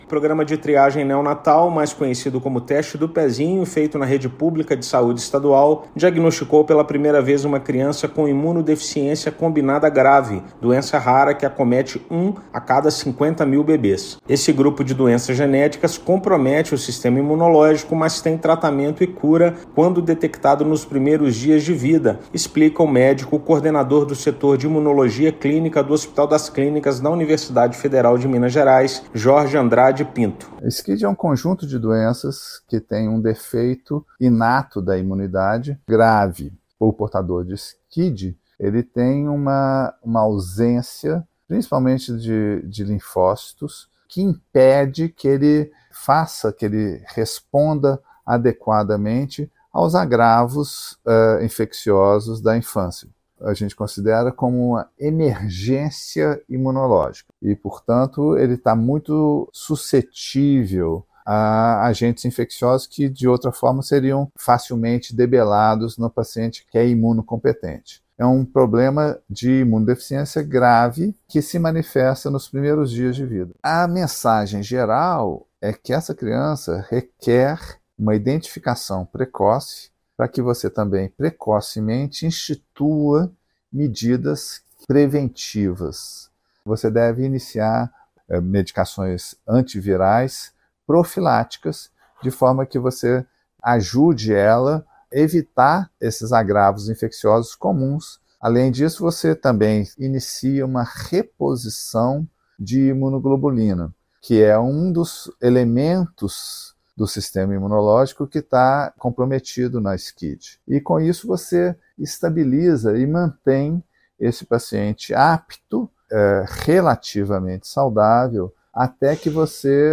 Doença rara afeta o sistema imunológico e o diagnóstico precoce é essencial. Ouça matéria de rádio.